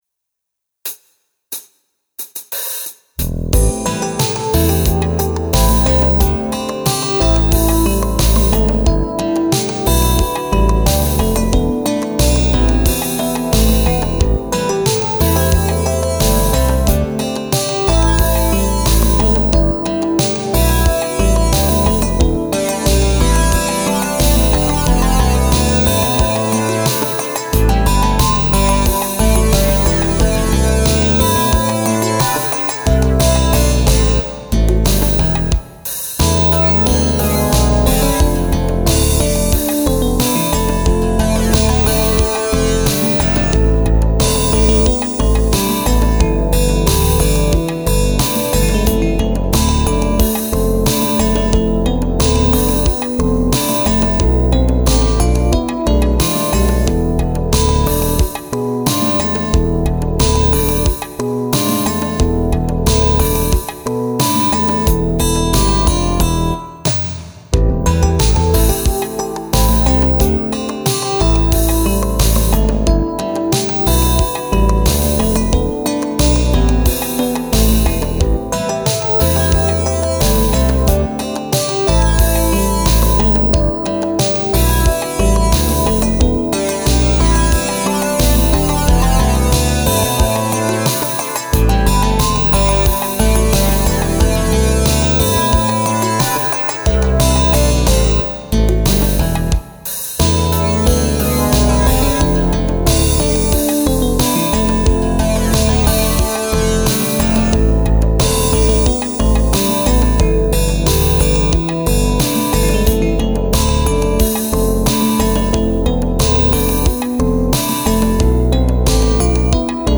音源は、ＳＣ８８２０を購入したので、さらに良くなっているはず。
かなりギターの書き方がうまくなり、いろんなギターが重なって曲を構成。